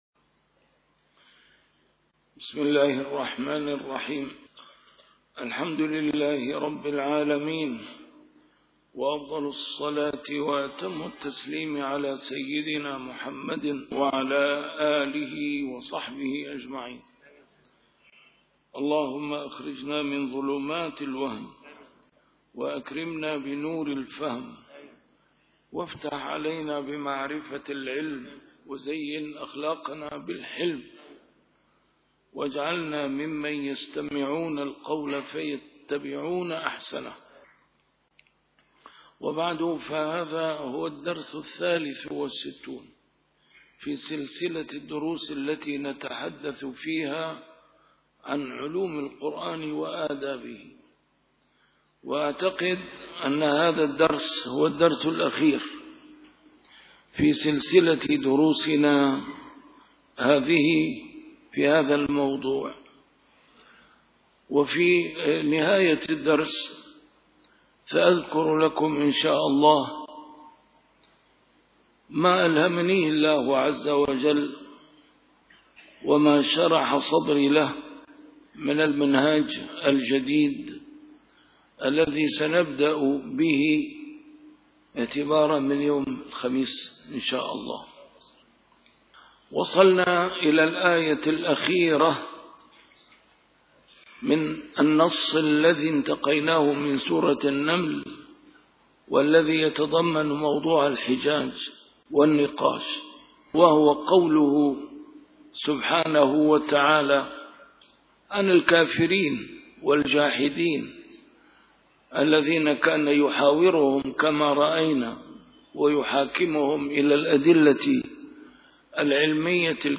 A MARTYR SCHOLAR: IMAM MUHAMMAD SAEED RAMADAN AL-BOUTI - الدروس العلمية - علوم القرآن كتاب (من روائع القرآن الكريم) - علوم القرآن / الدرس الثالث والستون - القسم الثالث: دراسات تطبيقية: في الحِجَاج والنقاش (بل ادَّارك علمهم في الآخرة بل هم في شكٍّ منها )